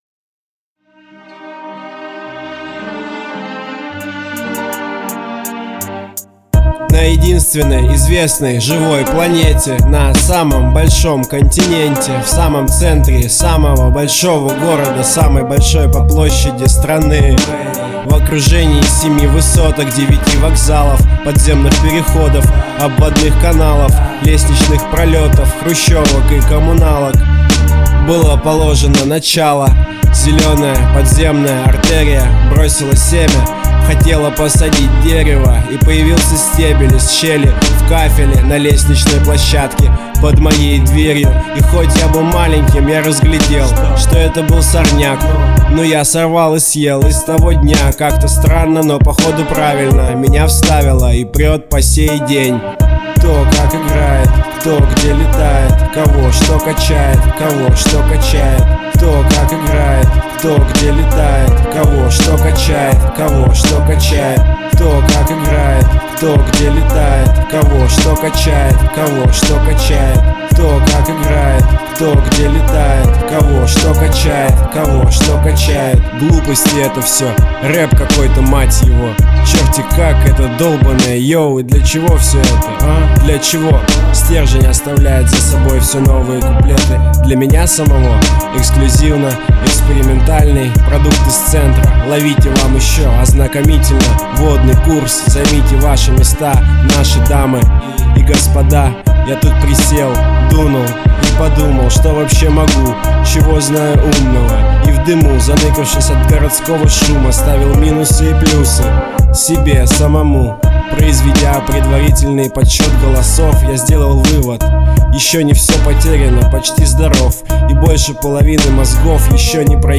Категория: Русский рэп